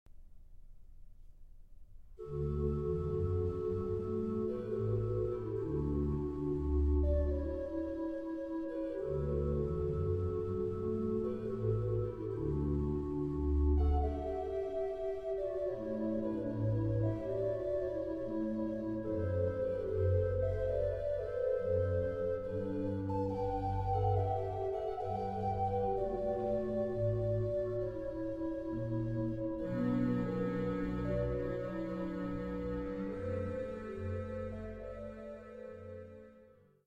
in Middelburg, The Netherlands